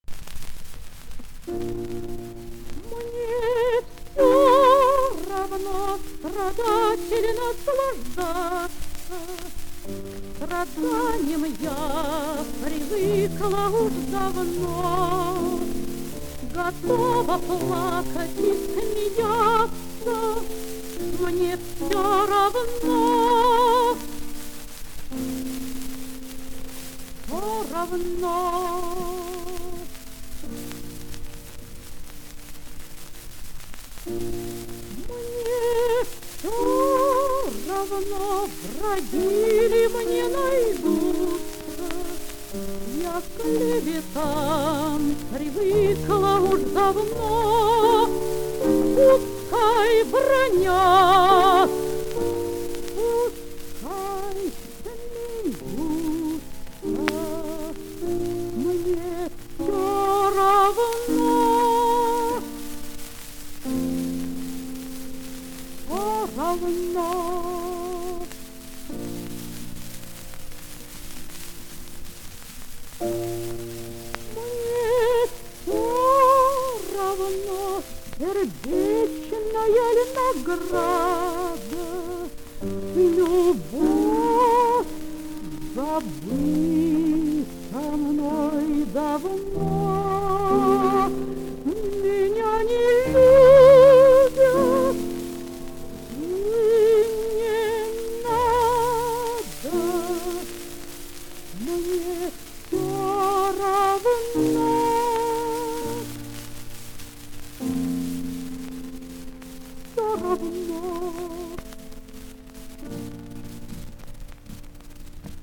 Романс «Мне всё равно». Исполняет М. П. Максакова. Партия фортепиано